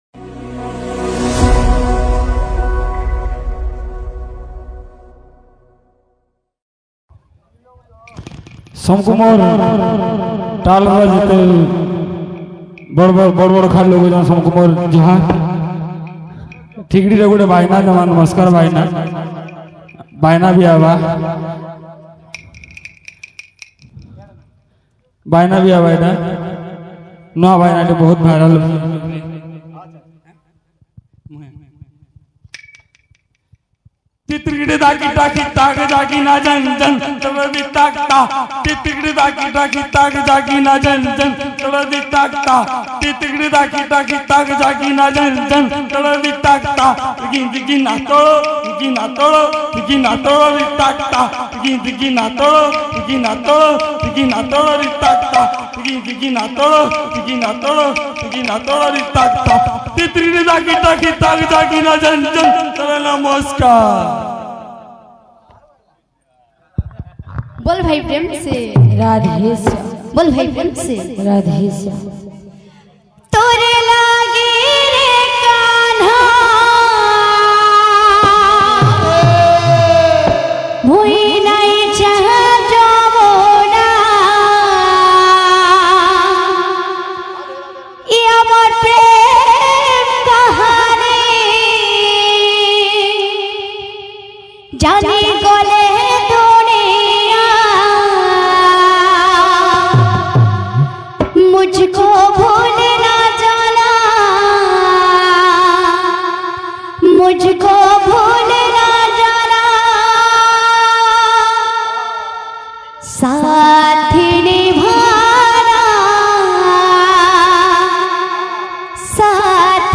Odia Kirtan Bhajan Songs